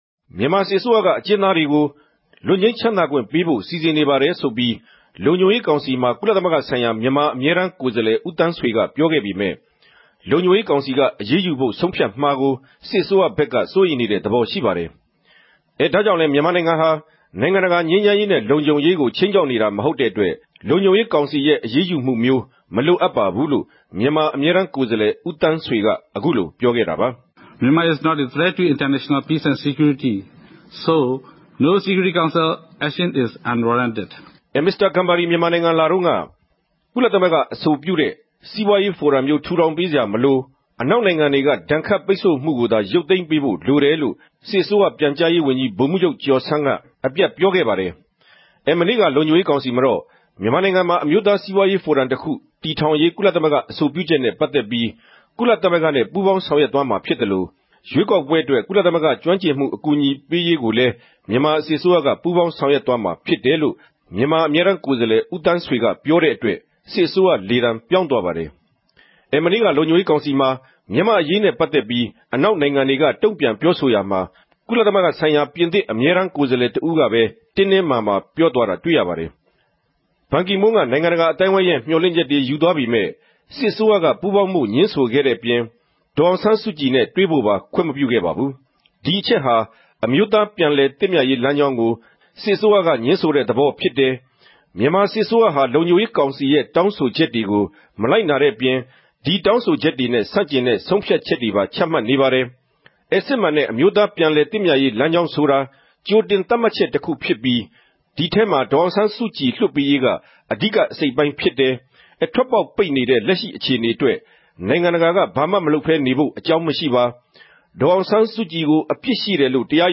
သုံးသပ်တင်ူပခဵက်။